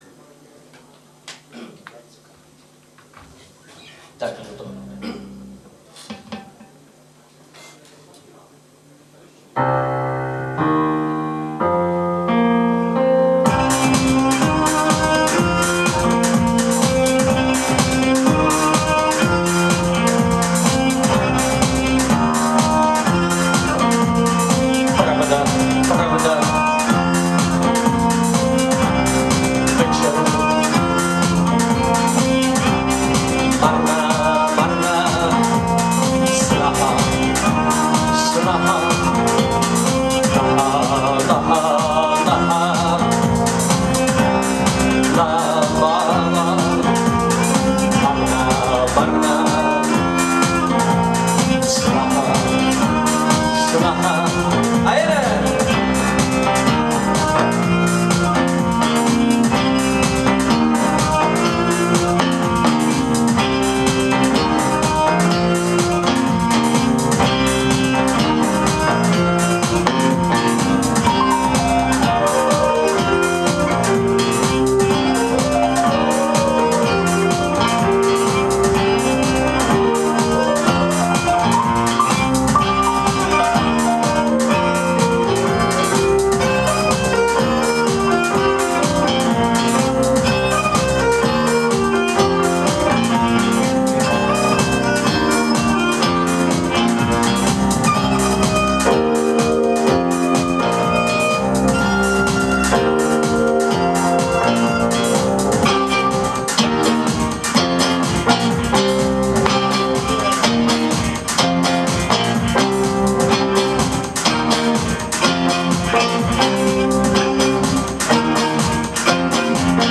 Alternativní hudba
Nahrávky z Valné hromady v Brně 25.1.2020